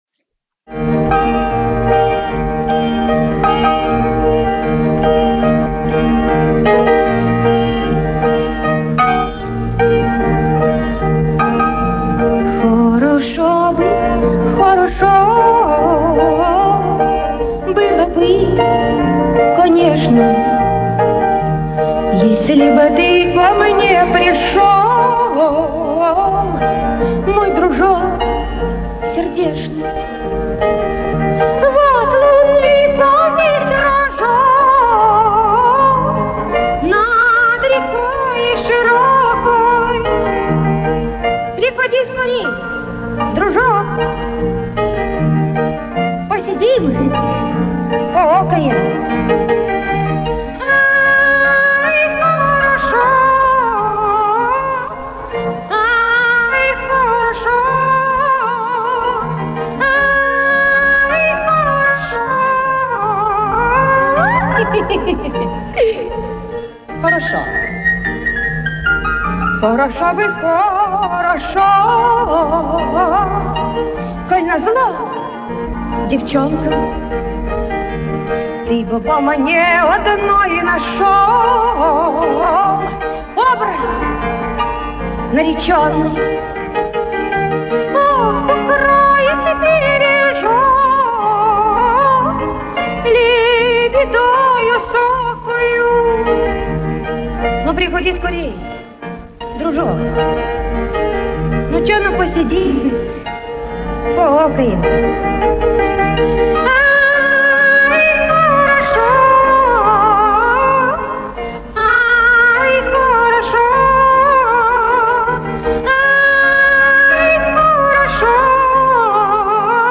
концерт в Польше.